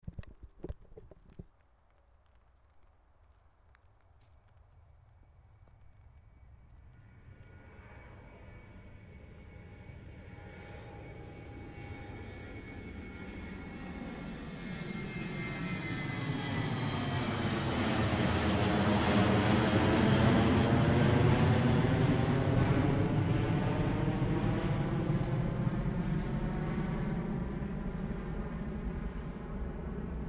Sound-Airplane-1.mp3